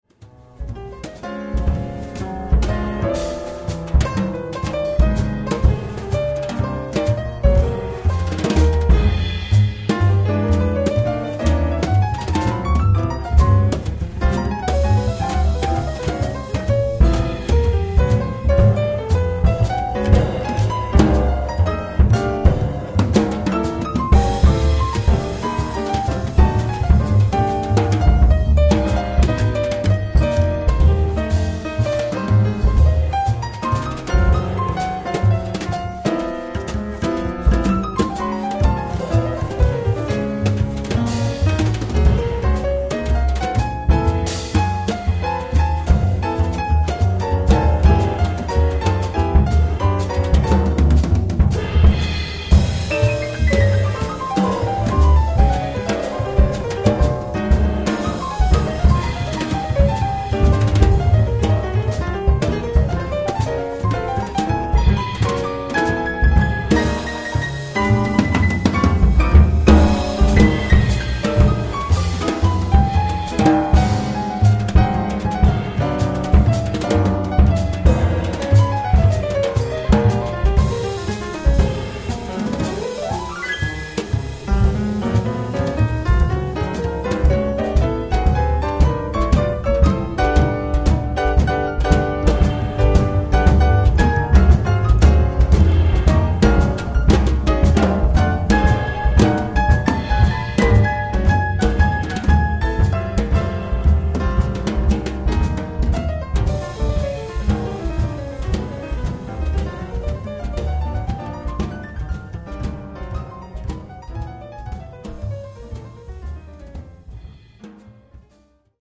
Piano
Vibrafon
Kontrabass
Schlagzeug, Spielsachen